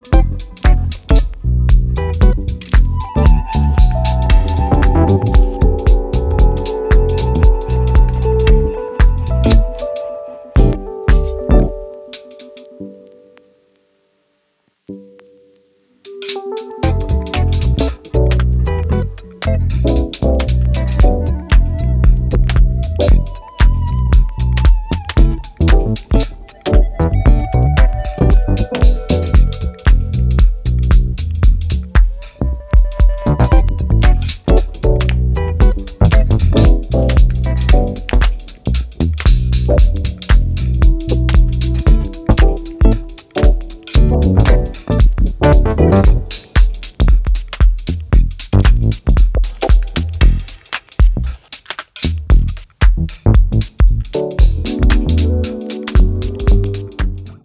an introspective downbeat roller